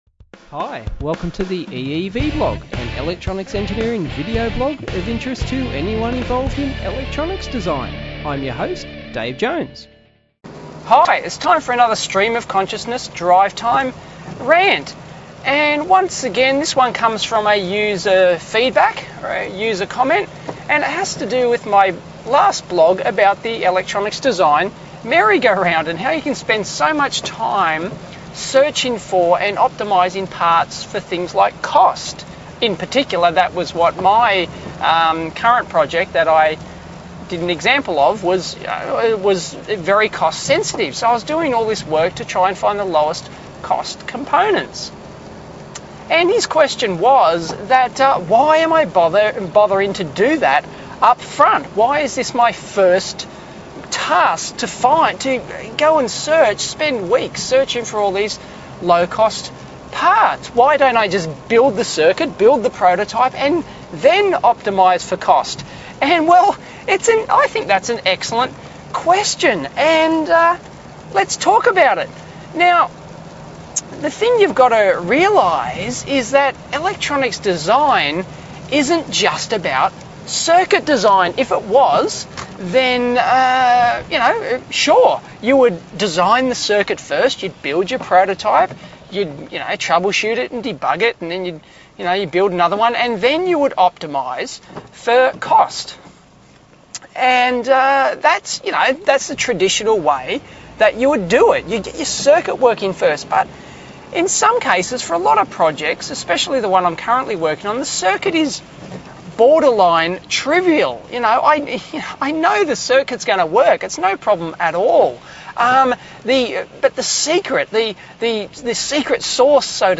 Drive time rant.